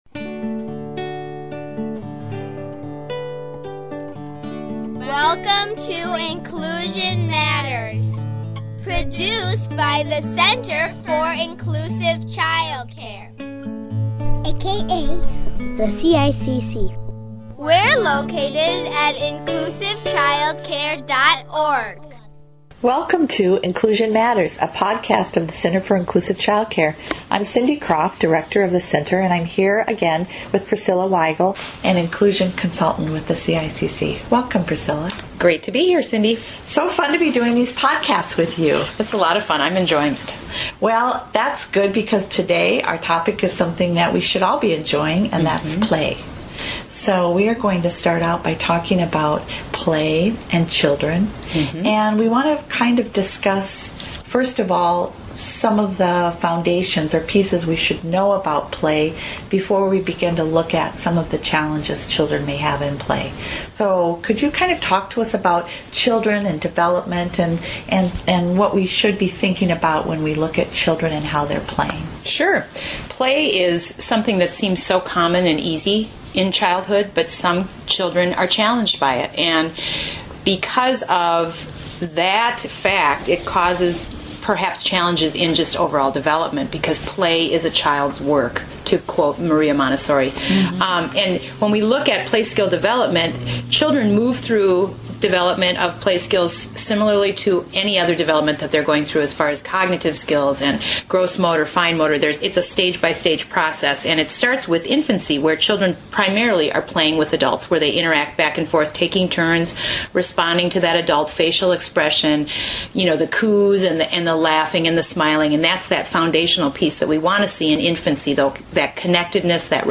Early childhood experts discuss a wide array of topics of interest to early childhood professionals